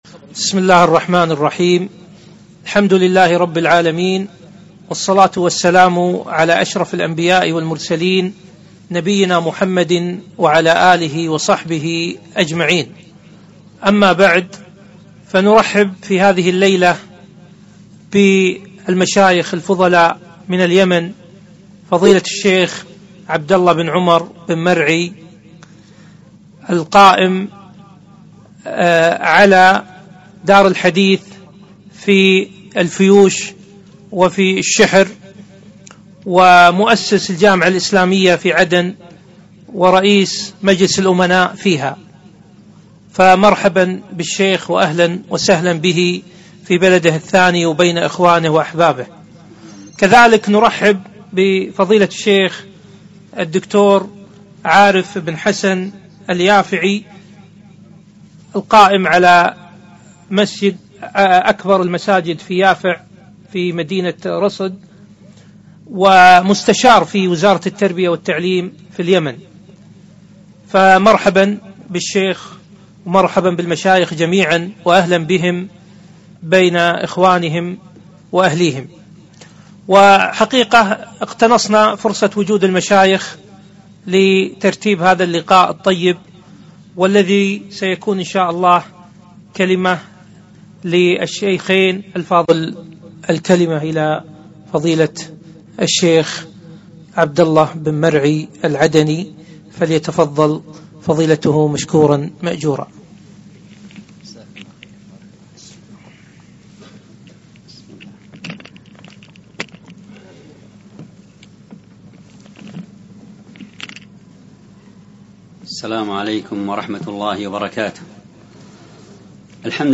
محاضرة - همة طالب العلم